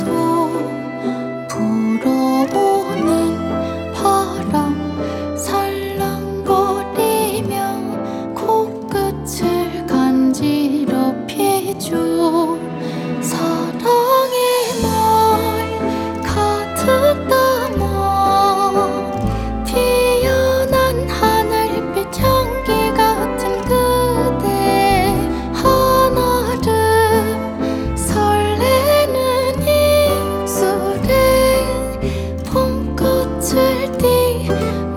K-Pop Pop Soundtrack
Жанр: Поп музыка / Соундтрэки